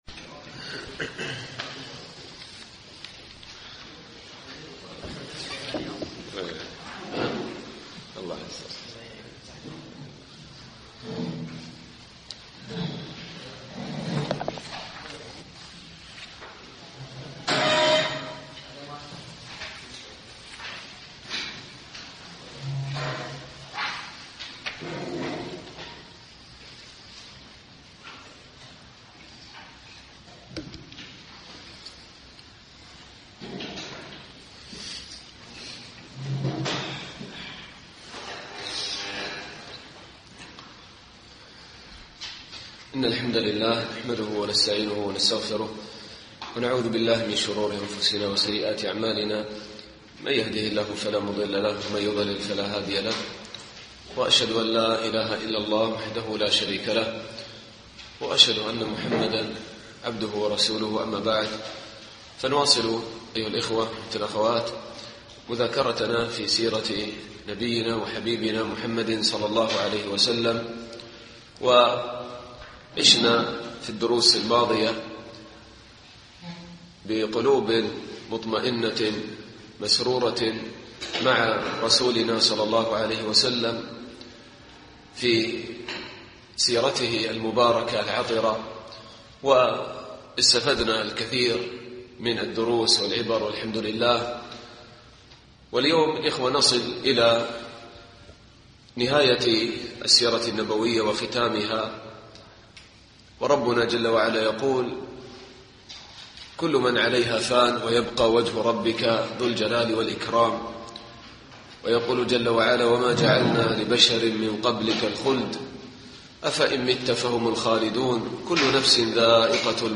الدرس الثاني والعشرون